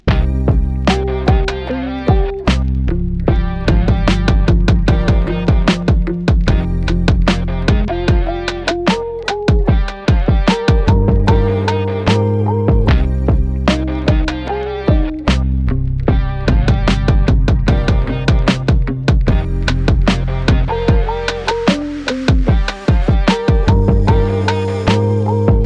Chill
Music - Song Key
C Minor
Cassino Bubbles
Woody Chill
Fendo Bass
Music - Shred Guitar
Sprinkle Spark